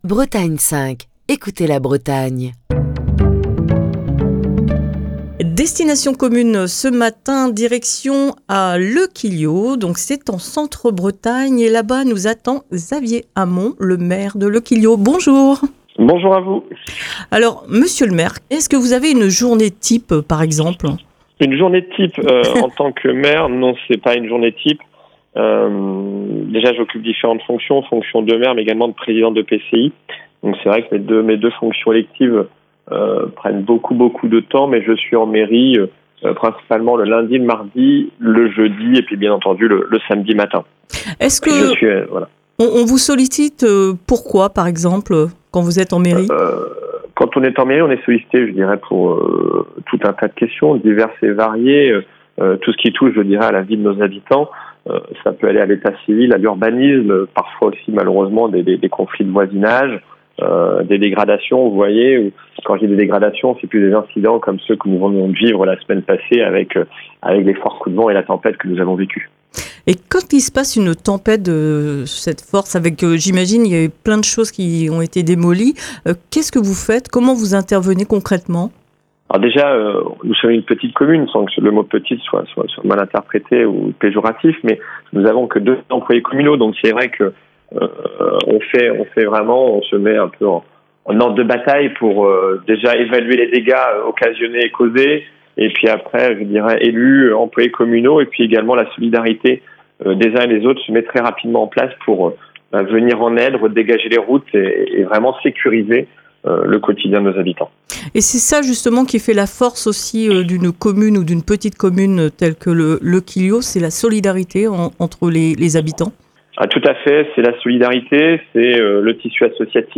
Xavier Hamon, le maire de Le Quillio est au micro de Destination commune.